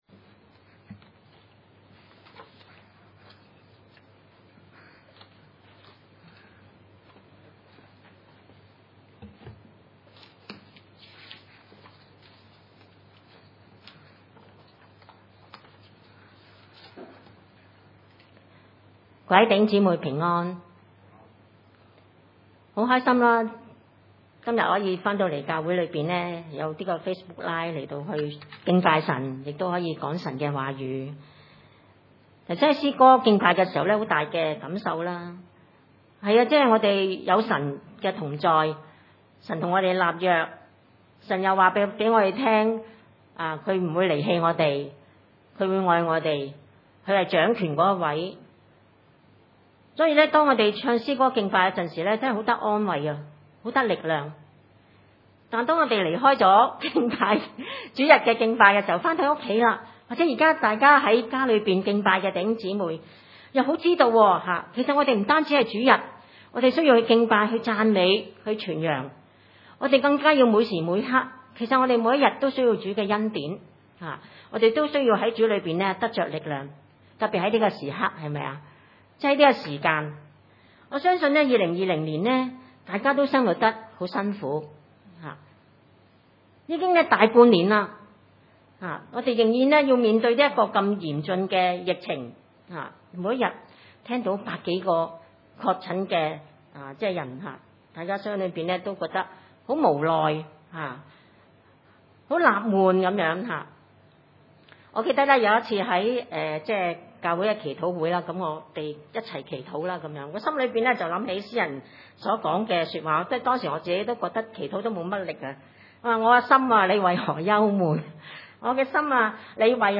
林前一10-19，三1-9 崇拜類別: 主日午堂崇拜 經文：哥林多前書第1章10-19節，第3章1-9節（聖經‧新約） 哥林多前書第1章10-19節 10.